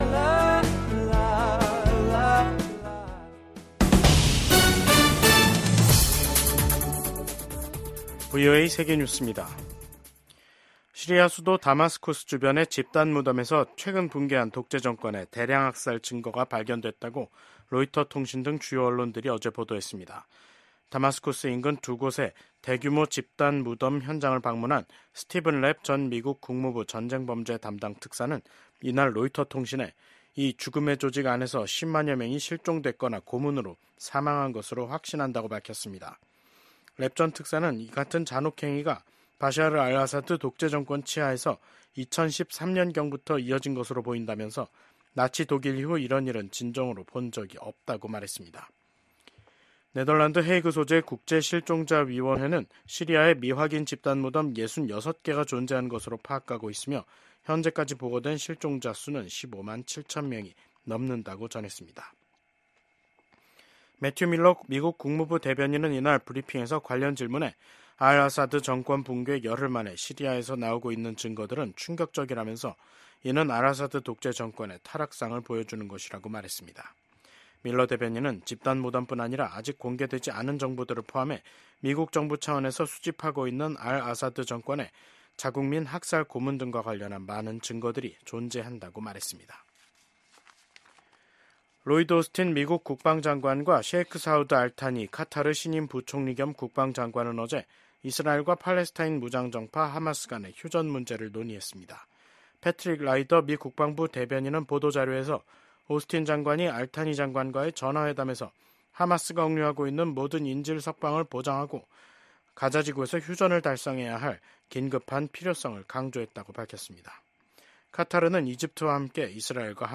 VOA 한국어 간판 뉴스 프로그램 '뉴스 투데이', 2024년 12월 18일 3부 방송입니다. 러시아에 파병된 북한군에서 수백 명의 사상자가 발생했다고 미군 고위 당국자가 밝혔습니다. 미국 국무부는 한국 대통령 탄핵소추안 통과와 관련해 한국 헌법 절차가 취지대로 작동하고 있다고 평가했습니다.